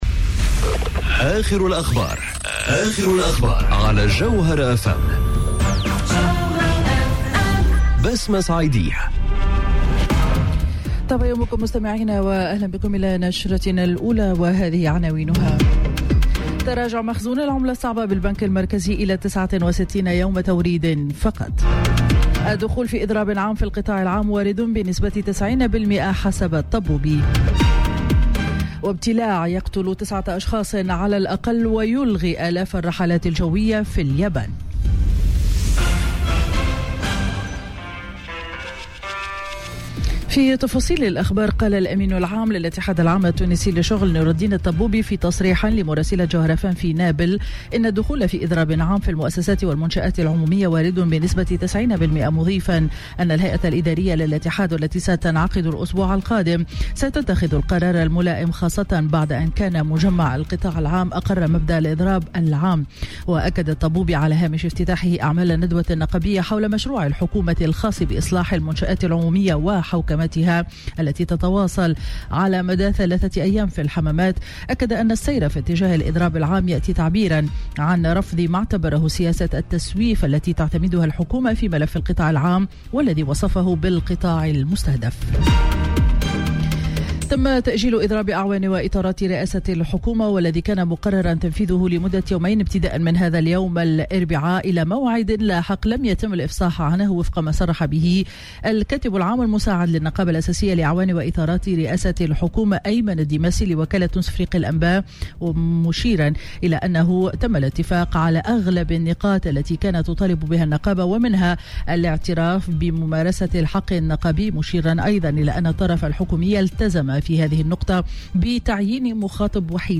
نشرة أخبار السابعة صباحا ليوم الإربعاء 5 سبتمبر 2018